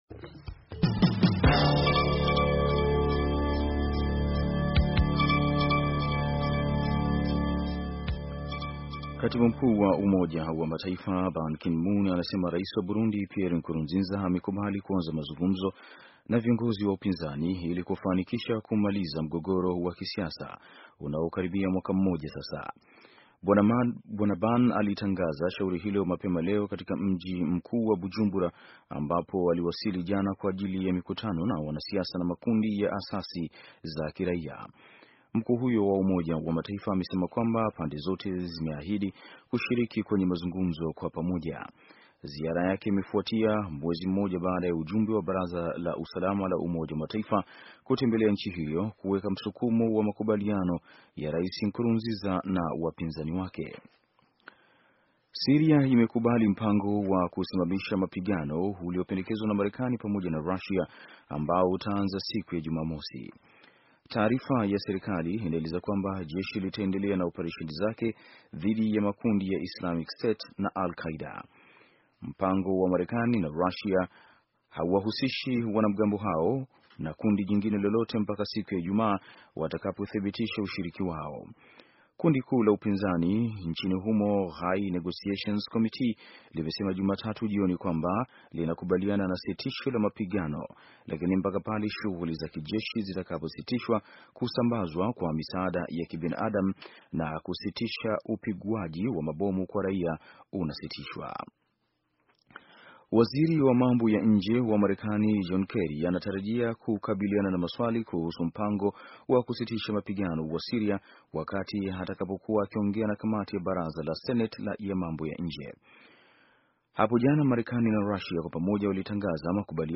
Taarifa ya habari - 5:55